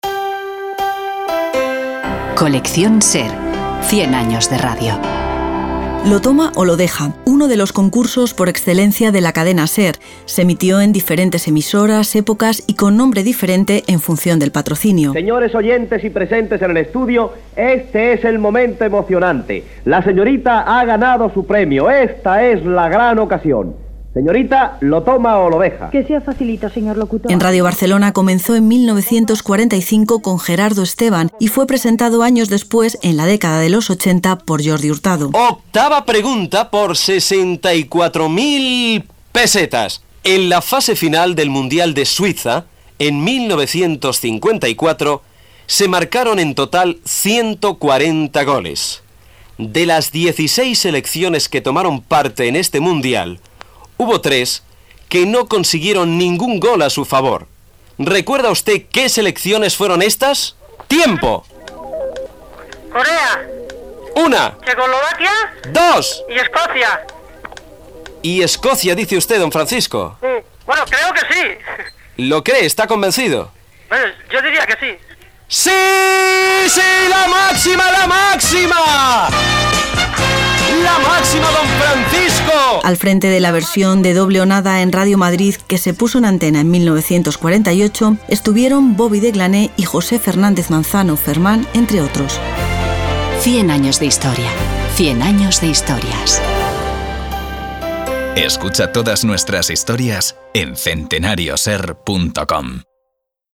'¿Lo toma o lo deja?', uno de los concursos por excelencia de la Cadena SER que se emitió en diferentes emisoras, épocas y con nombre diferente en función del patrocinio.